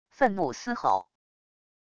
愤怒嘶吼wav音频